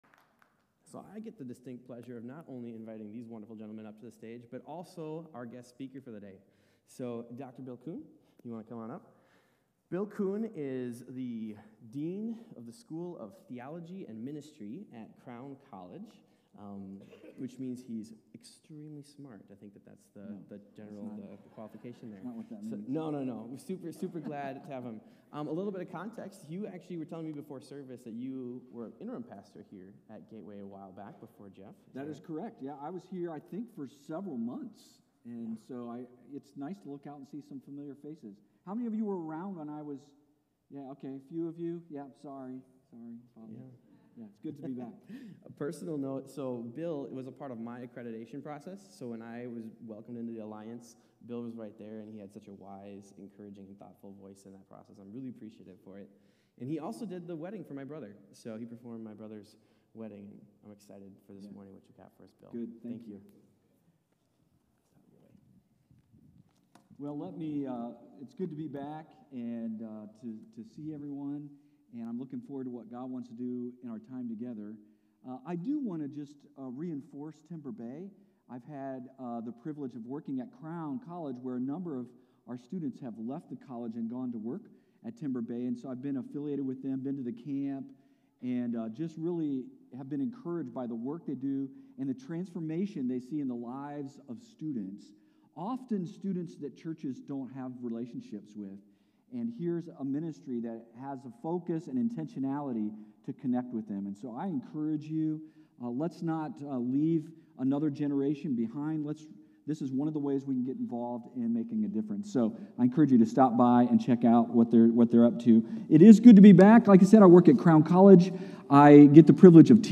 With-All-Your-Strength-Sermon-4-6-25.m4a